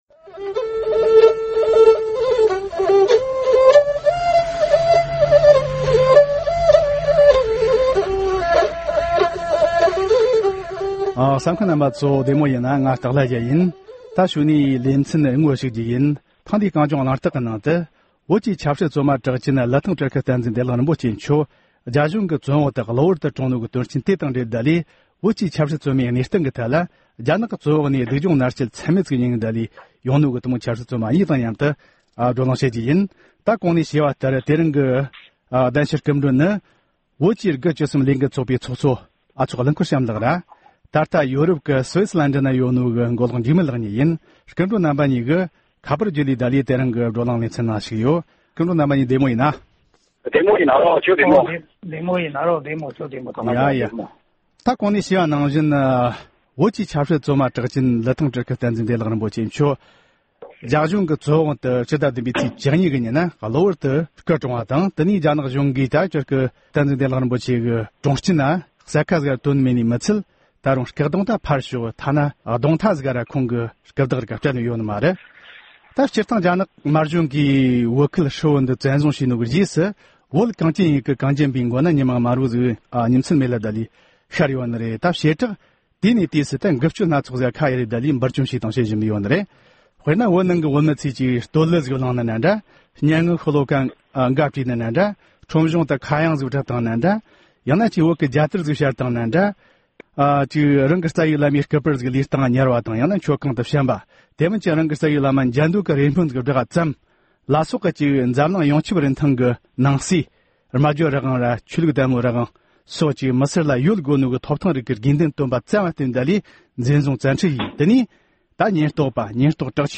༄༅། །ཐེངས་འདིའི་གངས་ལྗོངས་གླེང་སྟེགས་ཀྱི་ལེ་ཚན་ནང་། བོད་ཀྱི་ཆབ་སྲིད་བཙོན་པ་གྲགས་ཅན་ལི་ཐང་སྤྲུལ་སྐུ་བསྟན་འཛིན་བདེ་ལེགས་རེན་པོ་ཆེ་མཆོག་རྒྱ་གཞུང་གི་བཙོན་འོག་ཏུ་སྐུ་གྲོངས་པའི་དོན་རྐྱེན་དང་འབྲེལ། བོད་ཀྱི་ཆབ་སྲིད་བཙོན་མའི་གནས་སྟངས་ཀྱི་ཐད་རྒྱ་ནག་གི་བཙོན་འོག་ནས་སྡུག་སྦྱོང་མནར་གཅོད་ཚད་མེད་མྱངས་པའི་ཆབ་སྲིད་བཙོན་པ་གཉིས་དང་མཉམ་དུ་བགྲོ་གླེང་ཞུས་པ་ཞིག་གསན་རོགས་གནང་།